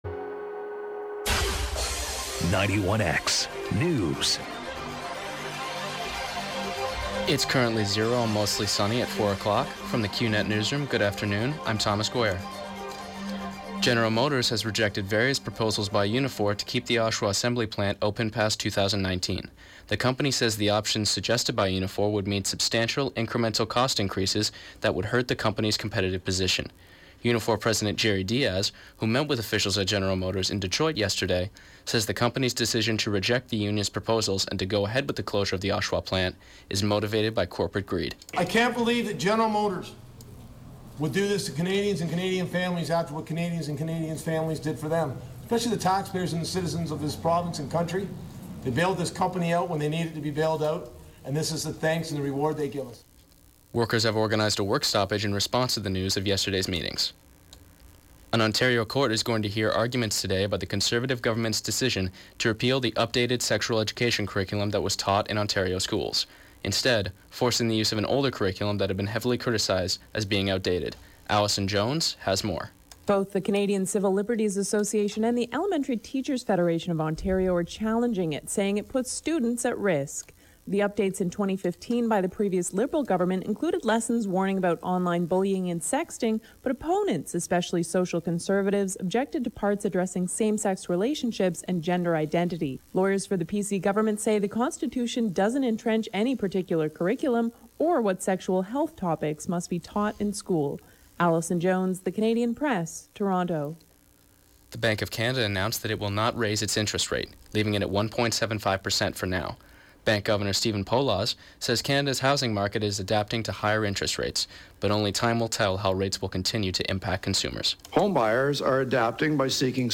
91x Newscast: Wednesday January 9, 2019